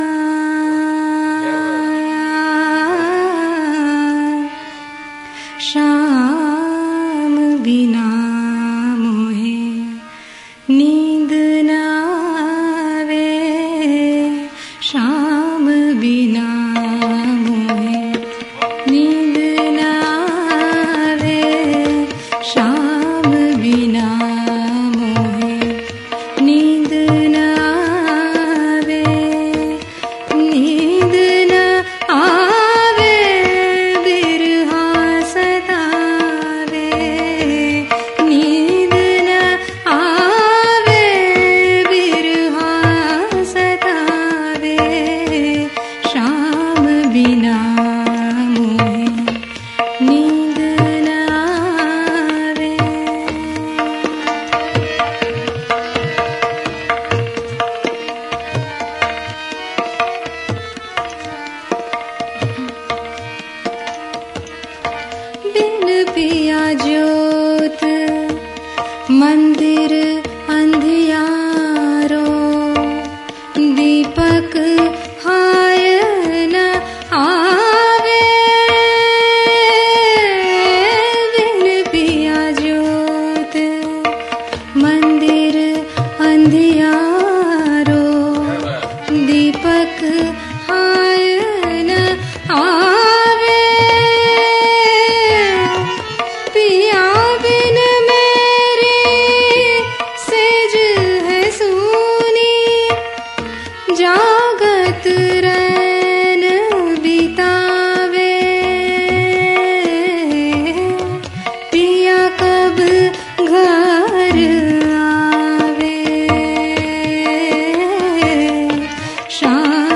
Early years recital